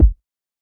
CardiakKick2.wav